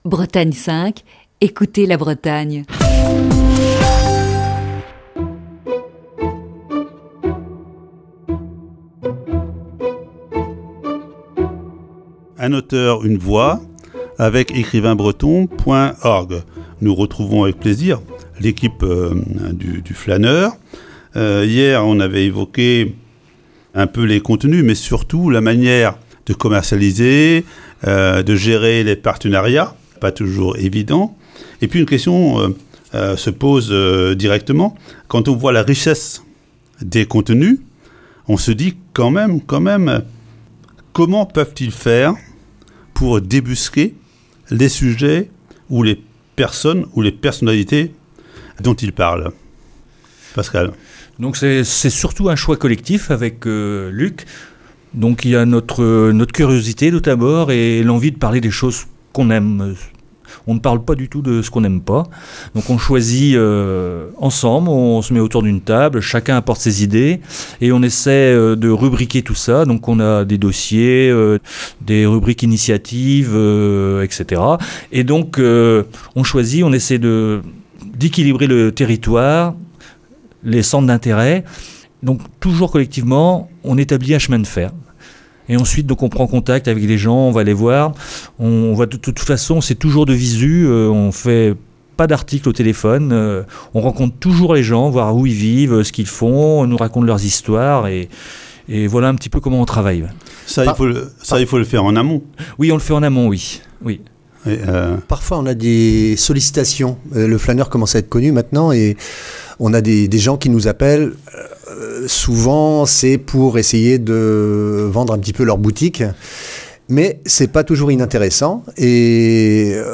Voici ce jeudi la quatrième partie de cette série d'entretiens.